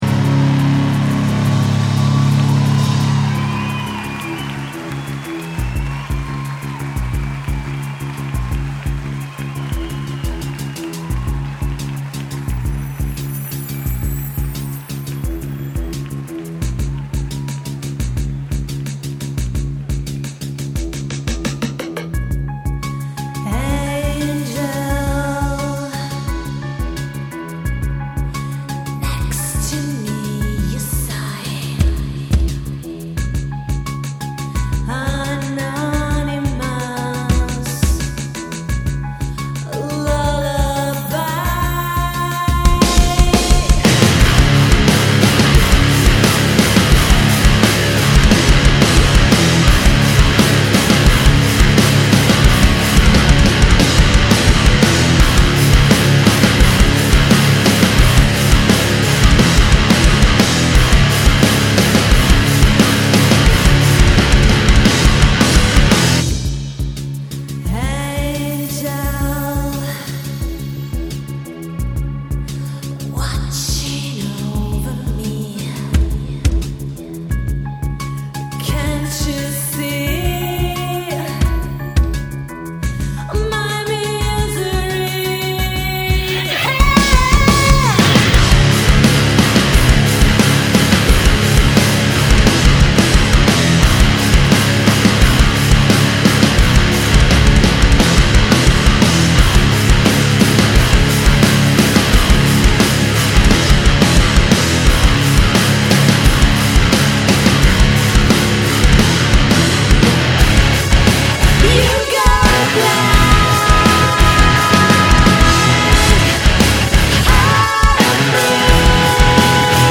Vocals
Lead Guitars
Drums
Rythm Guitars
bass and samples
Recorded Live